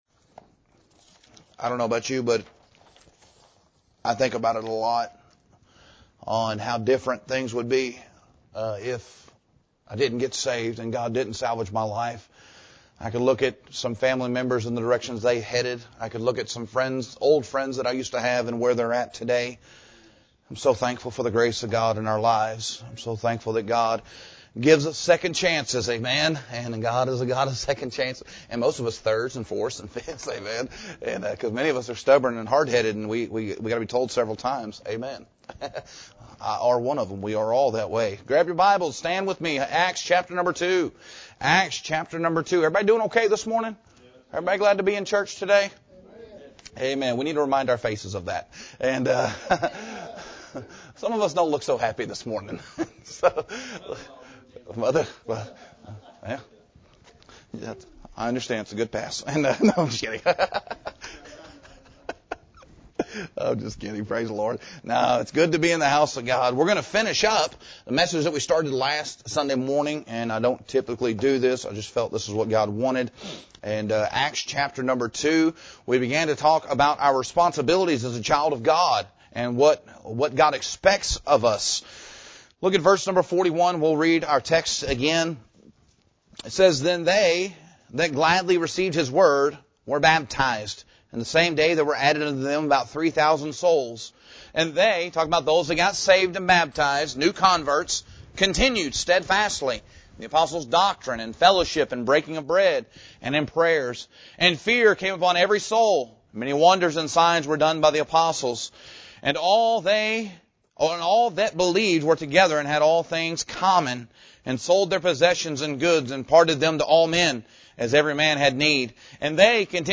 We return to Acts chapter 2 to finish a sermon series on the responsibilities of a Christian and a church. The early church in Acts provides a pattern of salvation, separation, service, sacrificial giving, unity, worship, and spiritual growth.